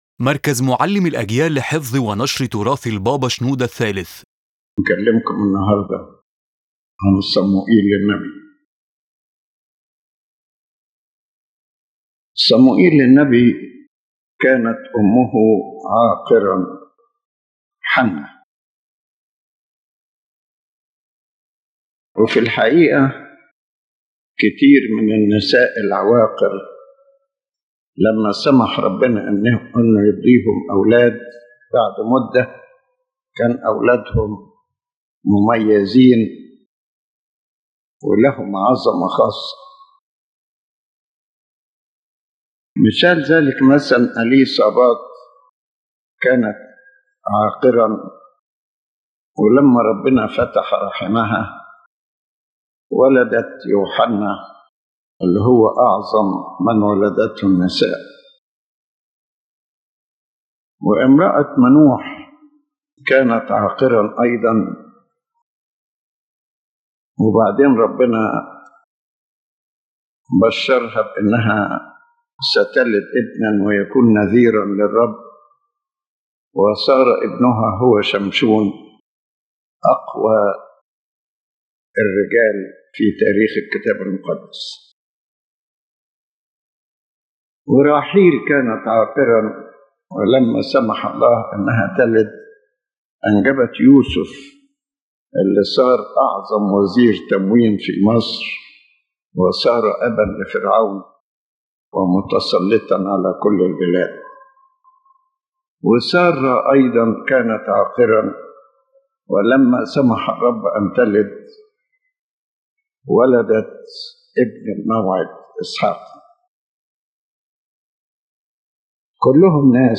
His Holiness Pope Shenouda III presents in this talk the life of the Prophet Samuel from his wondrous childhood, showing the depth of faith in which he was raised, and how God chose him as a prophet in a corrupt era to become the last judge of Israel and the greatest prophet after Moses. The lecture highlights the power of prayer, divine election, and Samuel’s humble obedience, and reveals God’s wisdom in leading the people, anointing kings, and establishing David as a king after God’s heart.